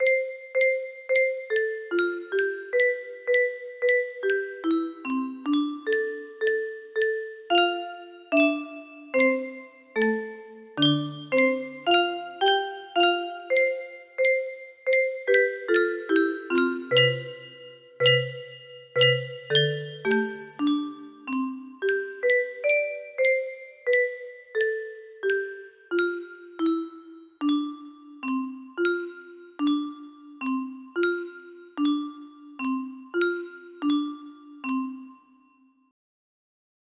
Whim music box melody